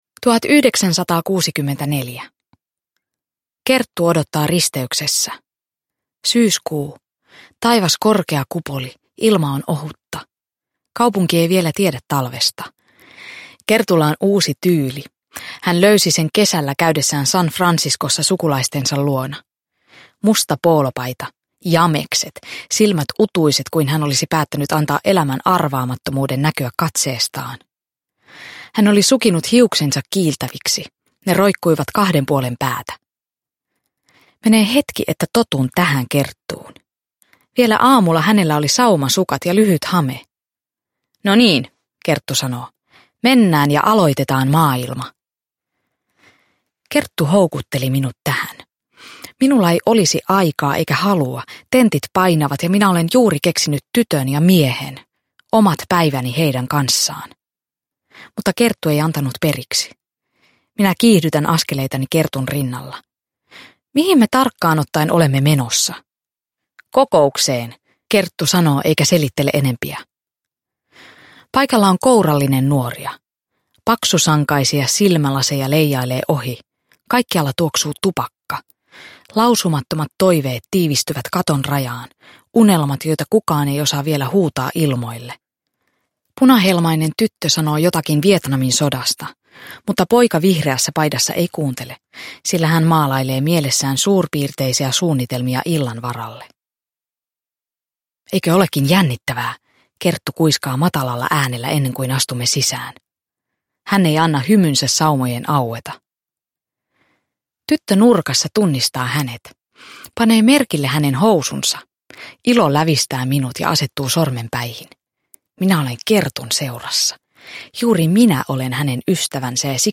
Totta – Ljudbok – Laddas ner